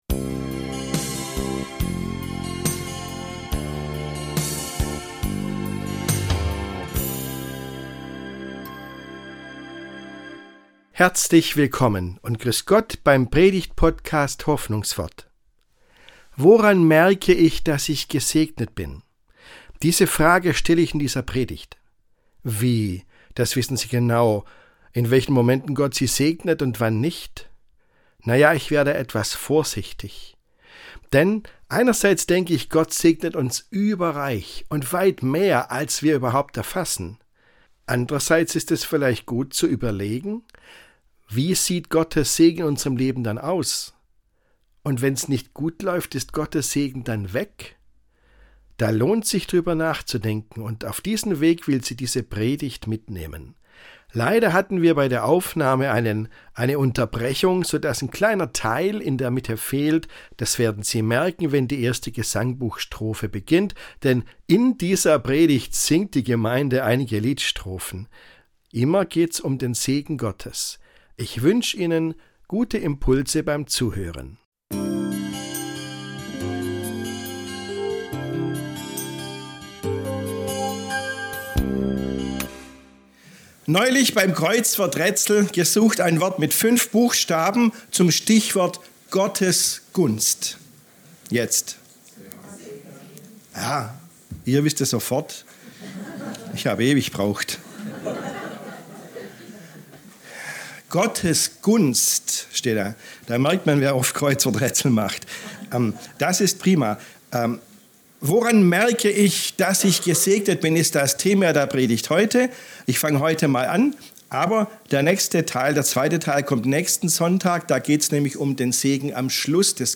Diese Predigt zeigt auf, wie überreich Gott uns segnet.